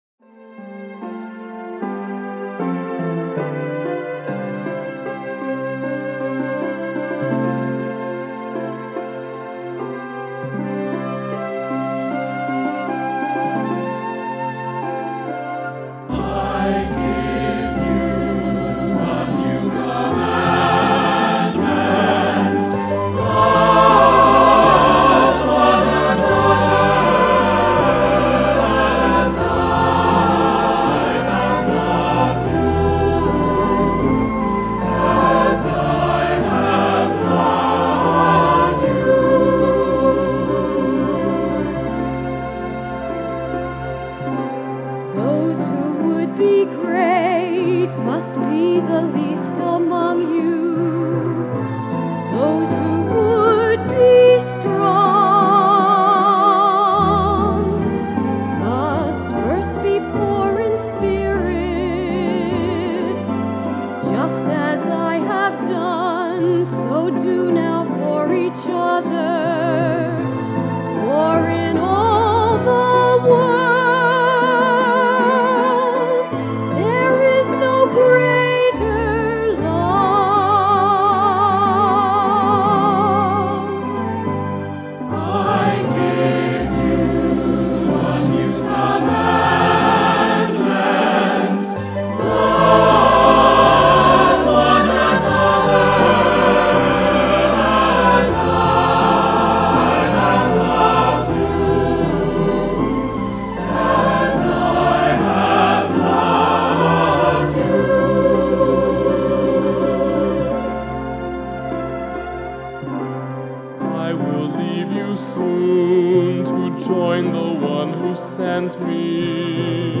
Voicing: SATB; Descant; Cantor; Assembly